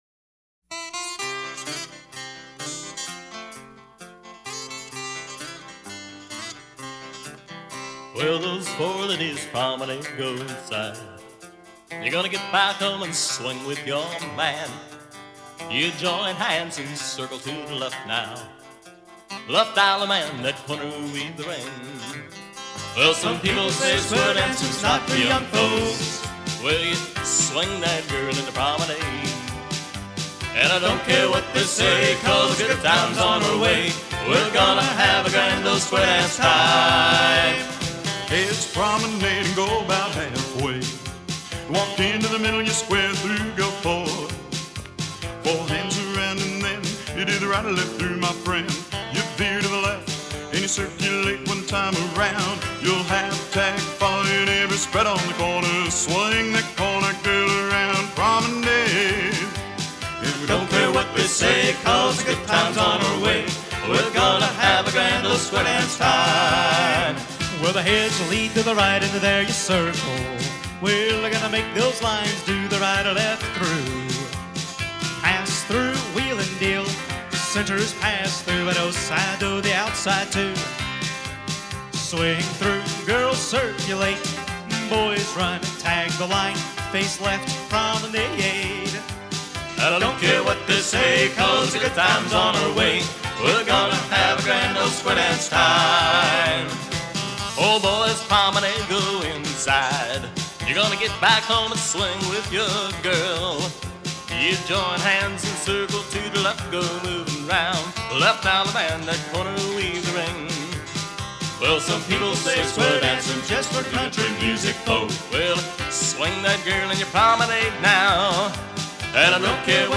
Record Type: Singing Call